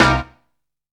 JONES STAB.wav